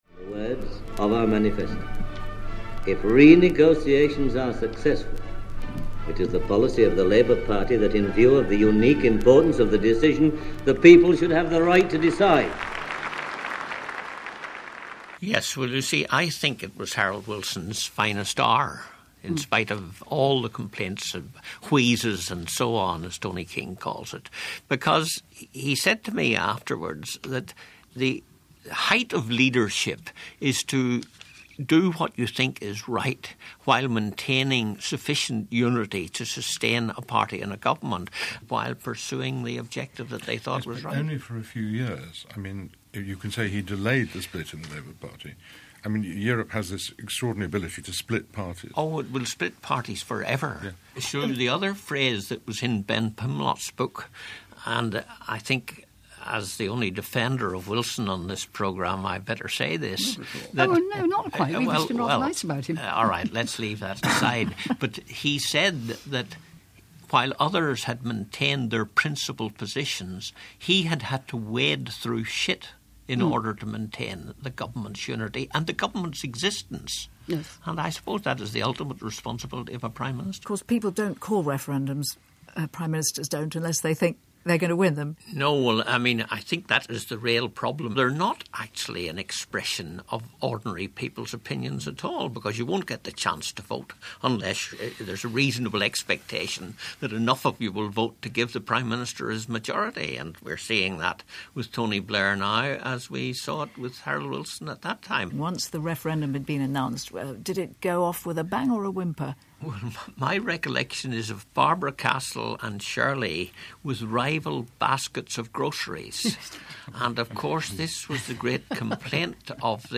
Here's one of his sterling performances, holding his own with Tony Benn and Shirley Williams in an episode of The Reunion recalling Harold Wilson's brave decision to hold a European referendum in 1975.